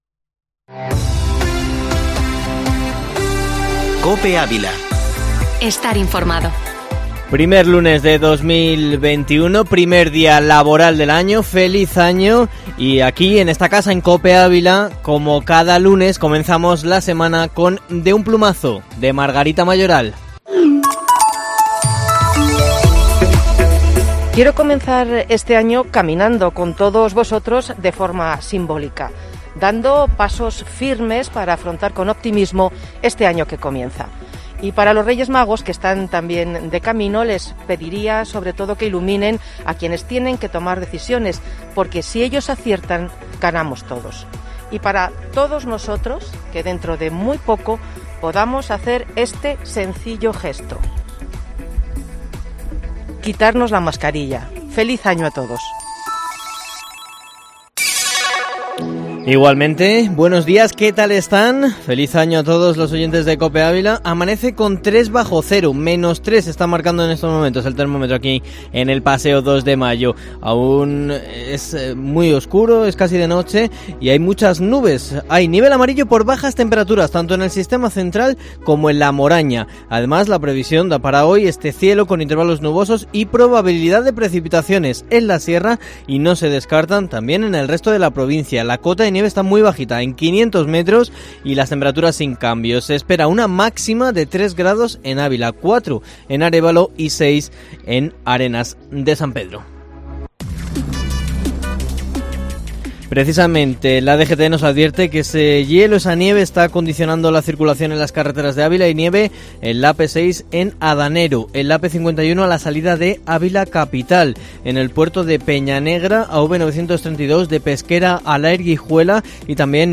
Informativo matinal Herrera en COPE Ávila 04/01/2021